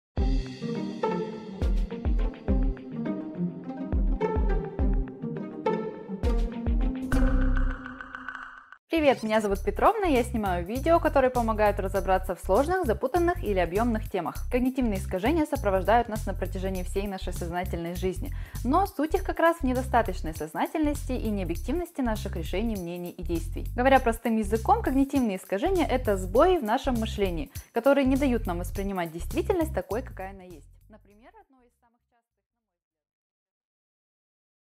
Аудиокнига Что такое когнитивные искажения | Библиотека аудиокниг